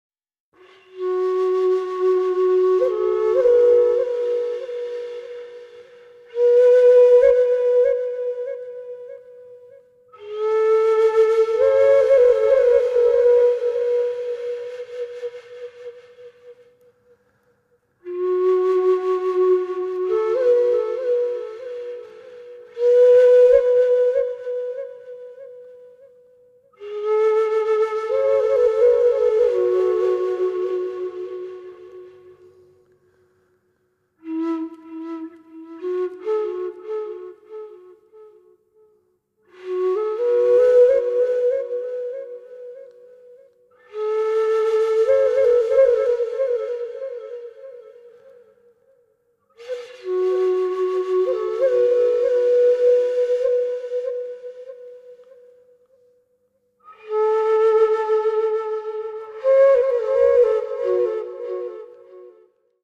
at studio Voice
虎杖笛、壷太鼓、ギター